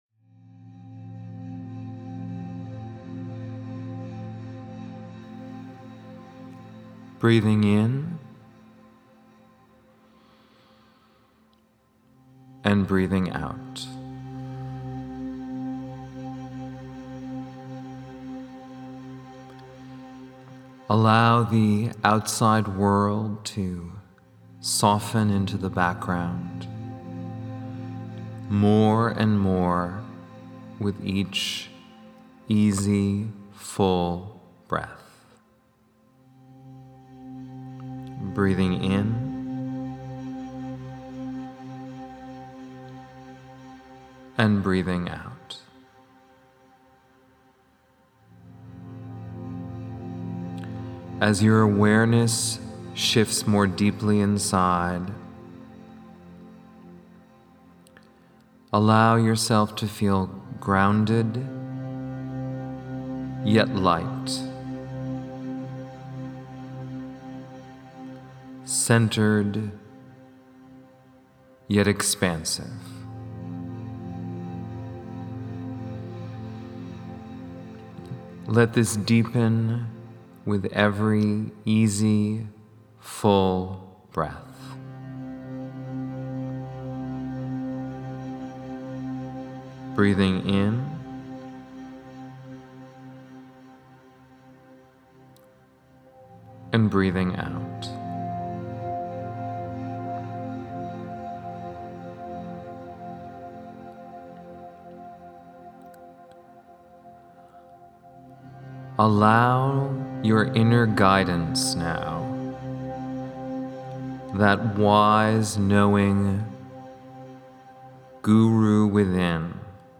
Pride-Meditation-1.mp3